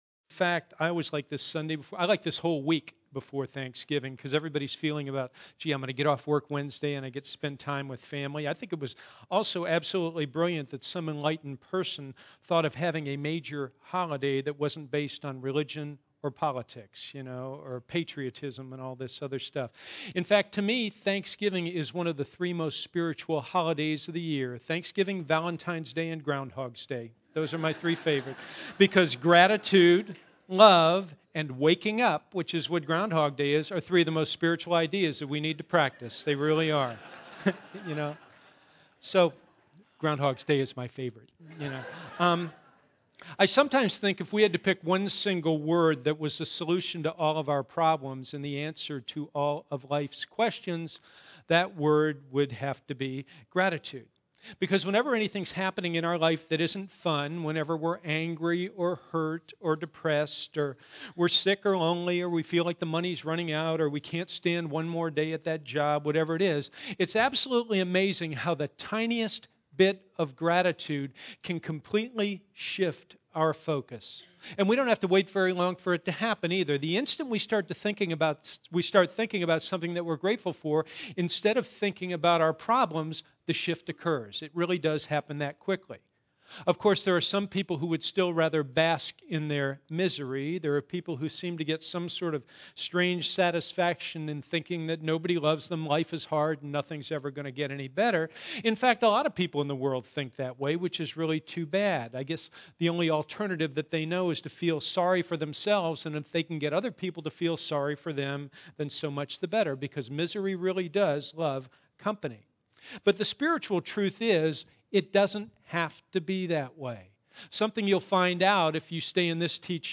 Our one-hour Sunday services are open and comfortable, with music, laughter, and interesting talks.